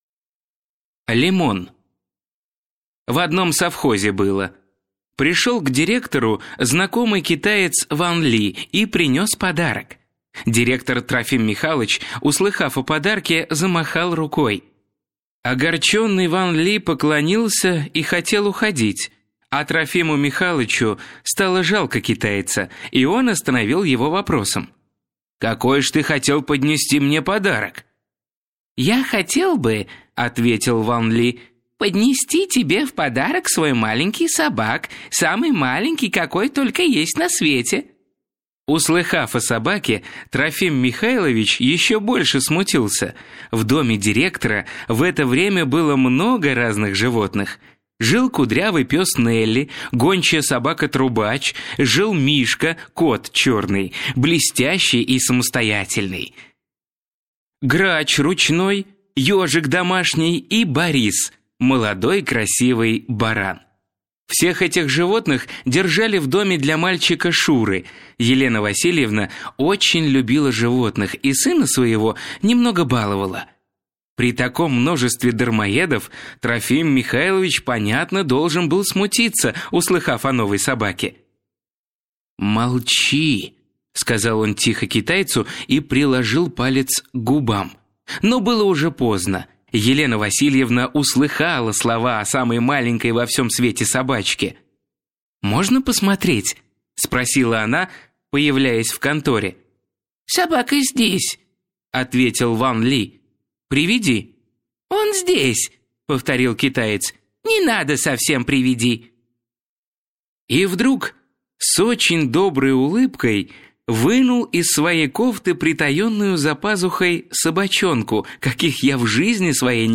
Аудиокнига Кладовая солнца | Библиотека аудиокниг